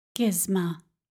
Click the icon below to hear the GSMA pronunciation (GIZ-mah):